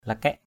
/la-kɛʔ/ (đg.) cày xới, cày trở. ngap hamu juai luai lakaik ZP hm~% =j& =l& l=kK làm ruộng đừng quên cày xới (cày trở.)